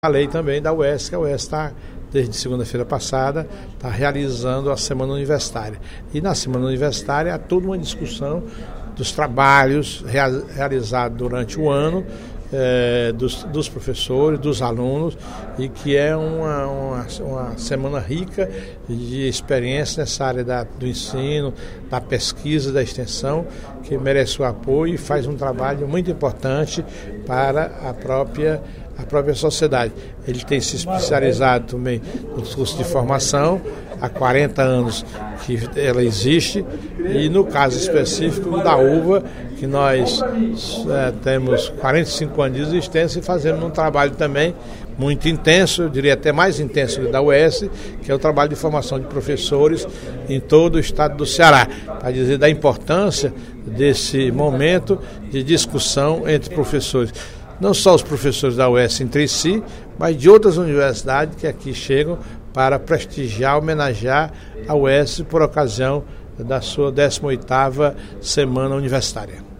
Durante o primeiro expediente desta quarta-feira (27/11), o deputado Professor Teodoro (PSD) homenageou Prisco Bezerra pela passagem de seu centenário.